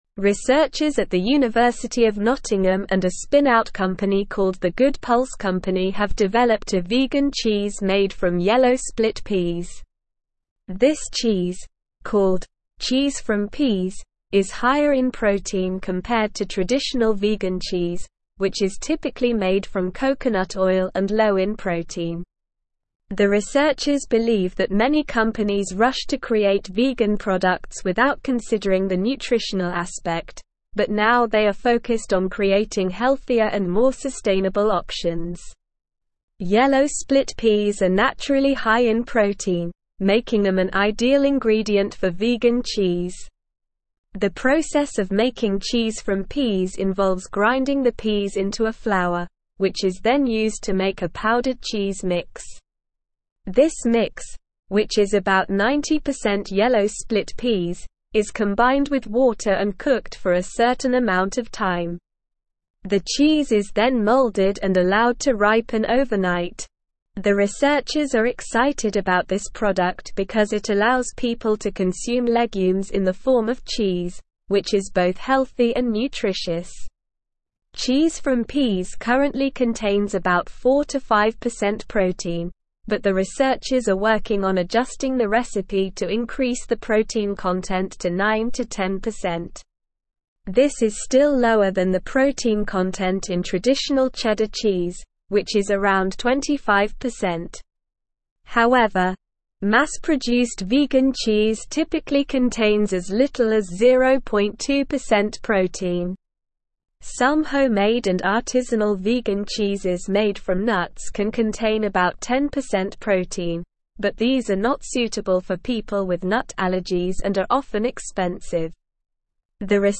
Slow
English-Newsroom-Advanced-SLOW-Reading-Vegan-Cheese-Made-from-Peas-High-Protein-Sustainable.mp3